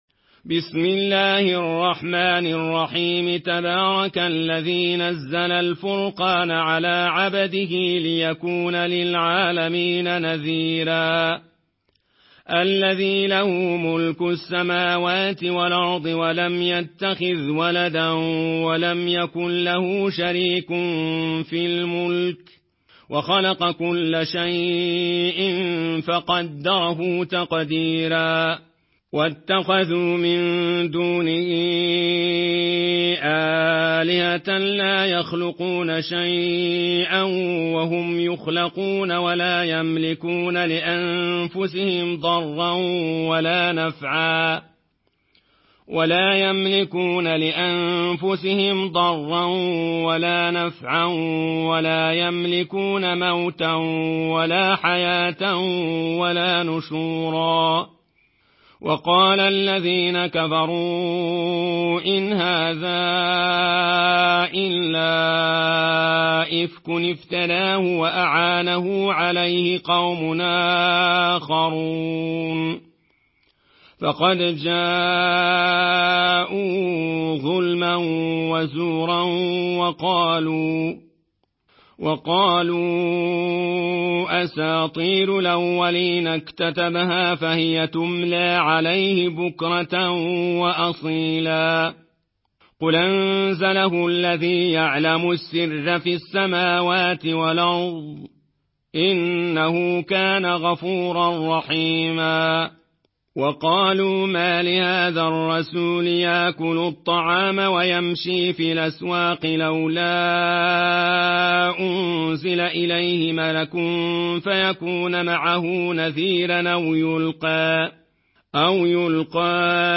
Murattal Warsh An Nafi